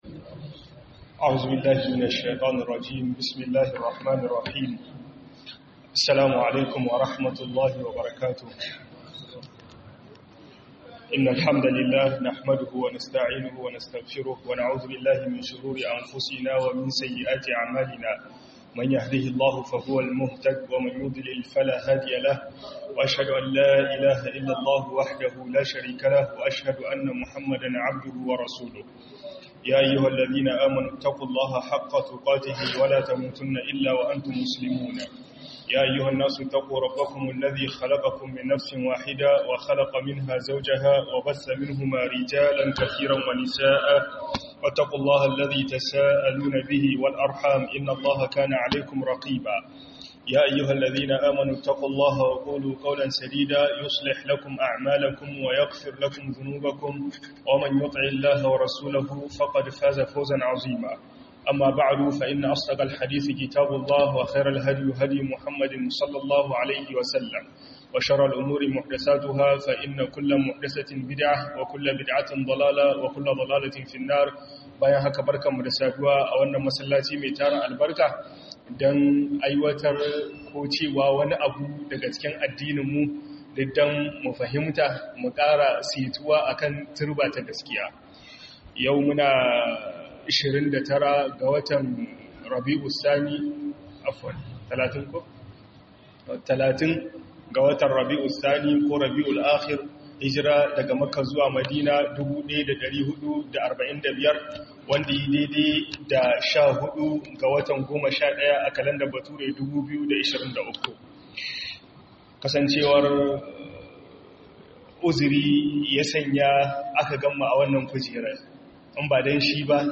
HALIN MUNAFIKAI ACIKIN AL'Qur'ani - HUDUBA